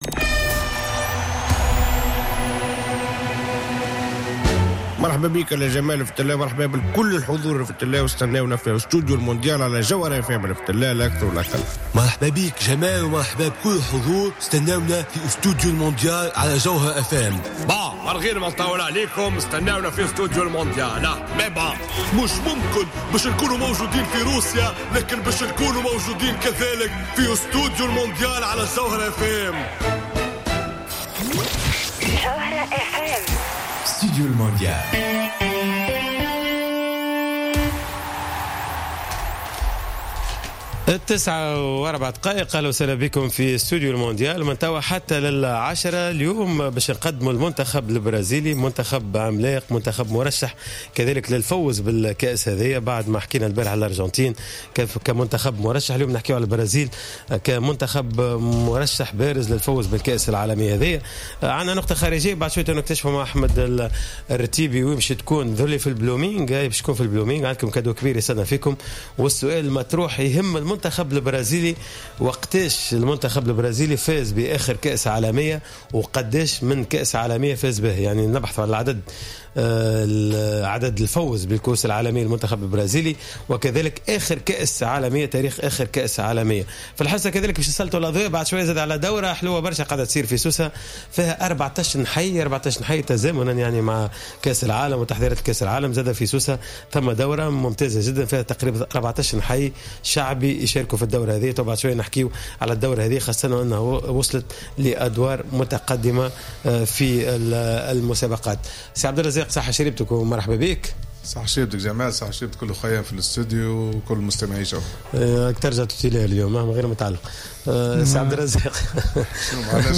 من قبل المحللين الفنيين